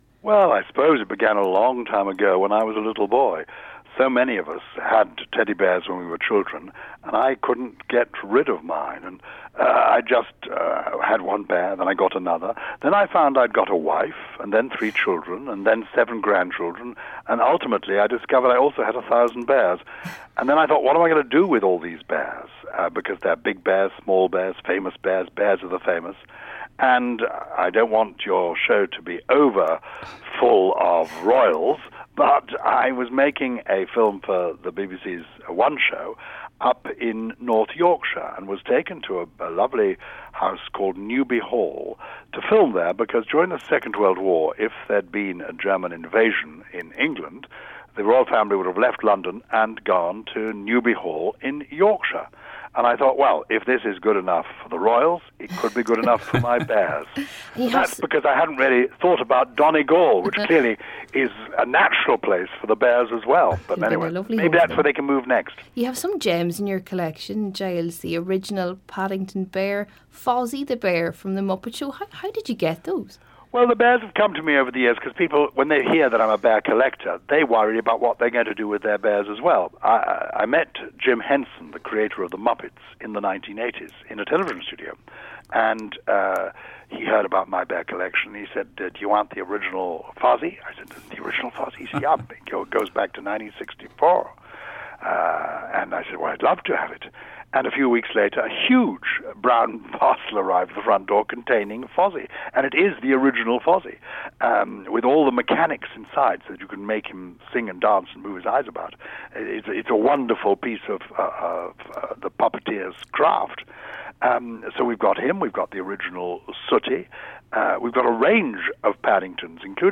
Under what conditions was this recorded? We were talking teddy bears on this morning's the Breakfast Show.